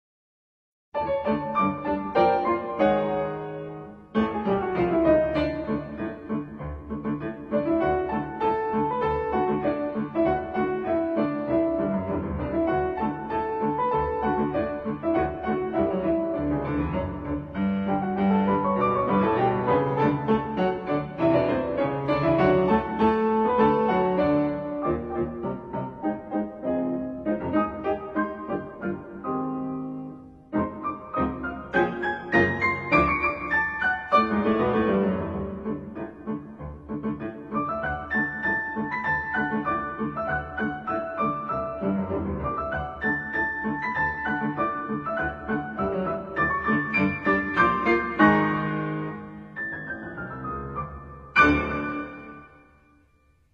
幽默欢快，耳熟能详